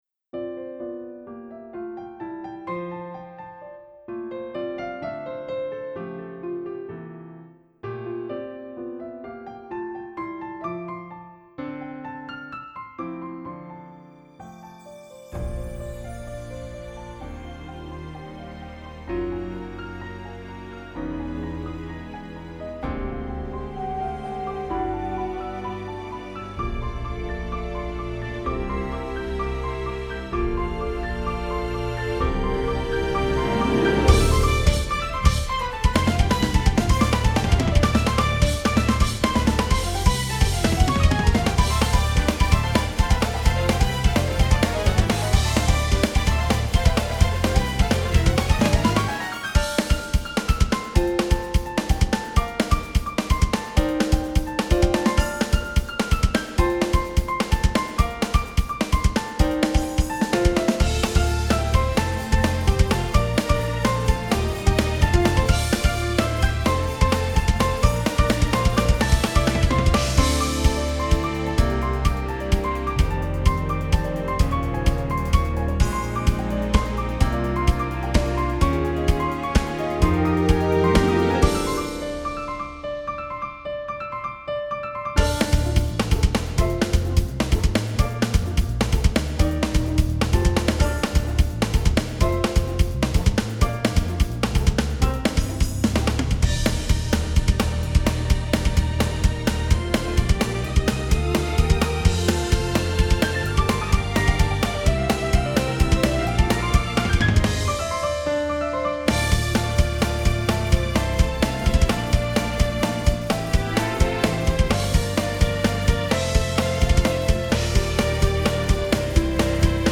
guitarless track